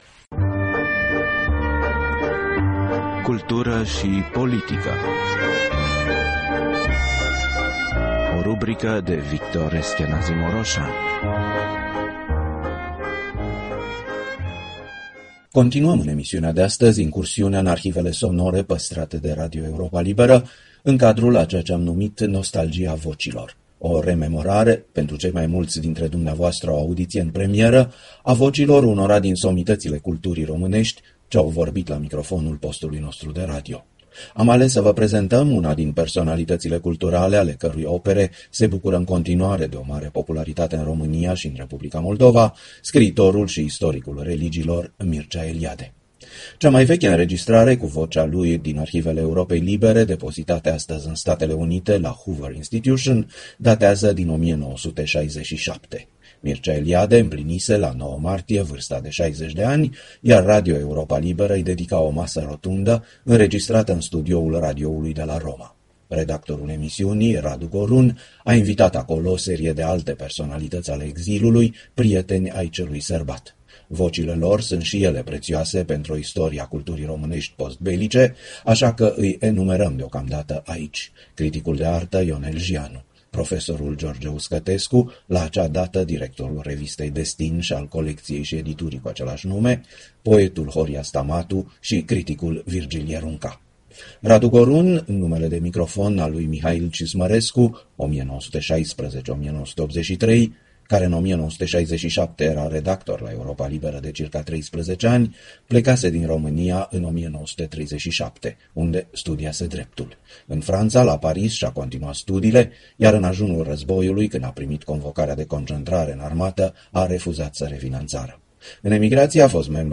O pagină autobiografică rostită de cărturarul român în cadrul unei mese rotunde prilejuită de aniversarea sa de 60 de ani.
Ascultați-l, așadar, pe Mircea Eliade cu o pagină autobiografică vorbită:
L-ați ascultat pe Mircea Eliade, vorbind la Radio Europa Liberă în 1967, în cadrul unei mese rotunde prilejuită de aniversarea sa de 60 de ani.